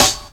• 00's Rap Steel Snare Drum Sample F# Key 10.wav
Royality free steel snare drum sample tuned to the F# note. Loudest frequency: 4364Hz
00s-rap-steel-snare-drum-sample-f-sharp-key-10-sL0.wav